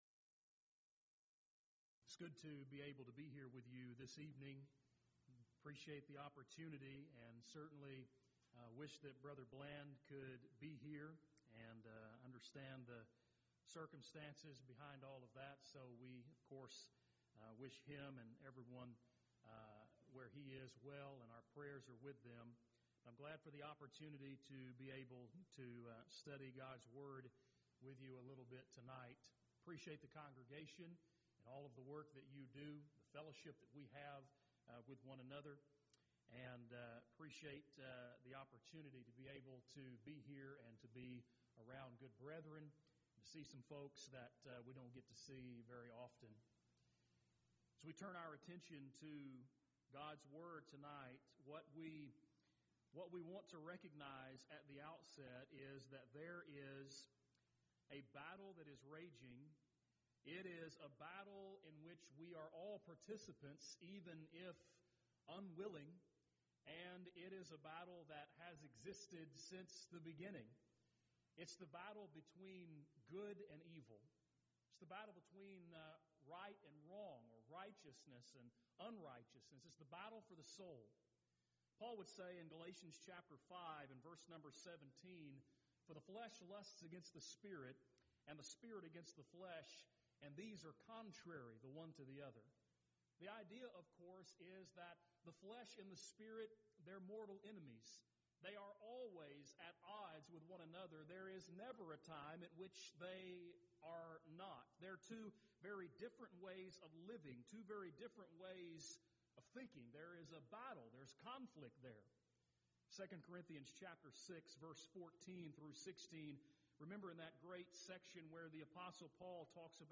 Event: 16th Annual Schertz Lectures Theme/Title: Studies in Genesis